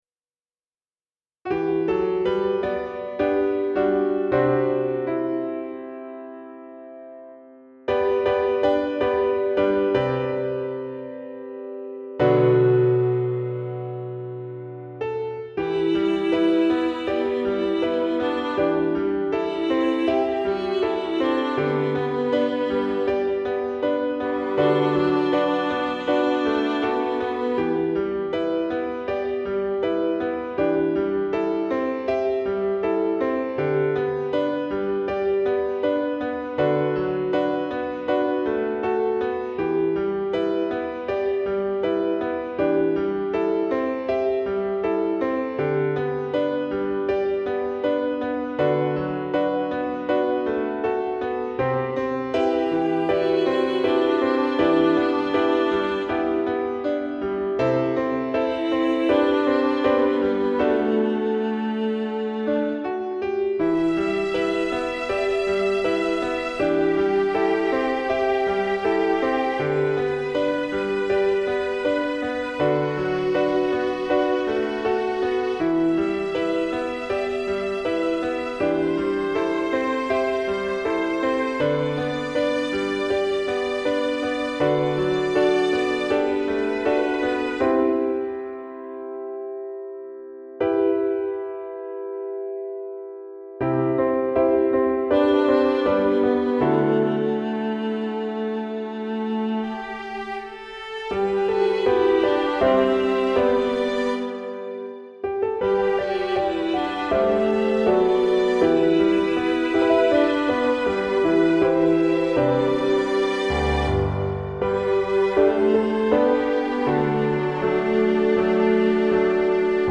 Wintersong-Choir-Backing.mp3